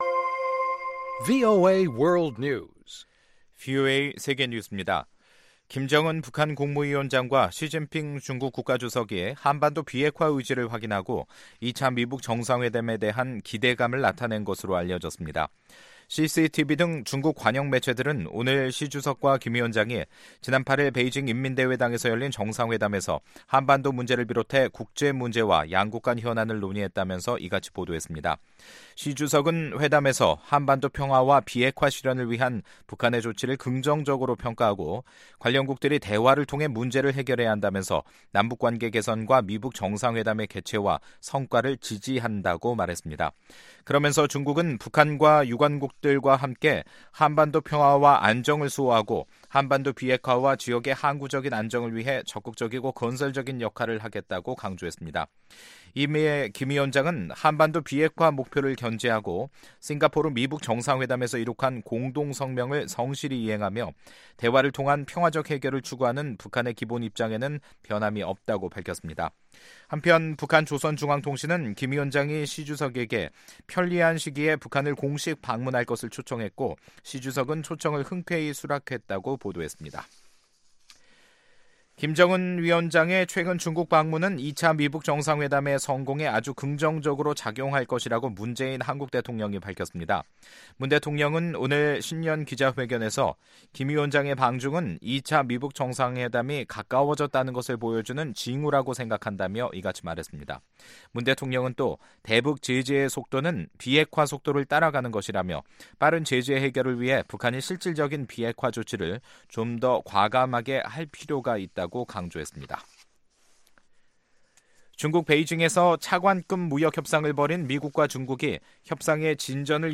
VOA 한국어 간판 뉴스 프로그램 '뉴스 투데이', 2019년 1월 10일 2부 방송입니다. 김정은 북한 국무위원장과 시진핑 중국 국가주석은 베이징 회담에서 2차 미-북 정상회담에 대한 기대감을 나타냈습니다. 워싱턴 주재 몽골대사는 VOA와의 인터뷰에서 몽골은 한반도 상황에 큰 영향을 받는 나라로서 미-북 정상회담과 남북관계 진전을 환영한다고 말했습니다.